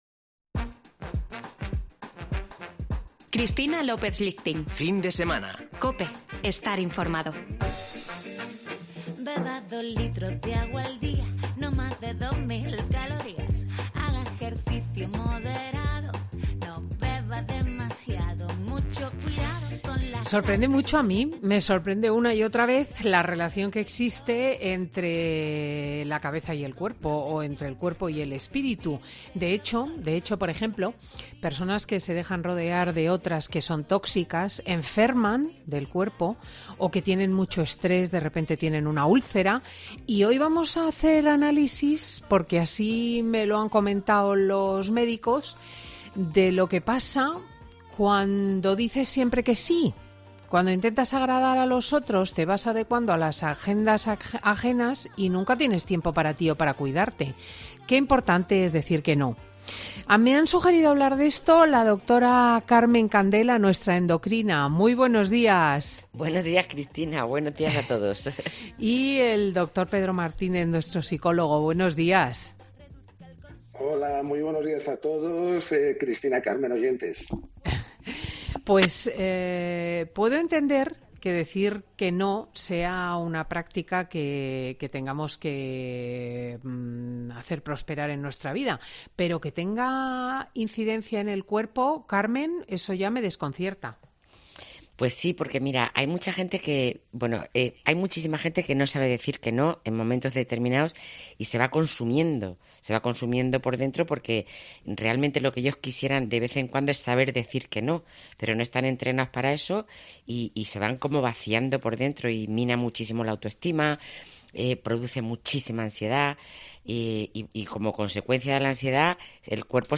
"Fin de Semana" es un programa presentado por Cristina López Schlichting
es un magazine que se emite en COPE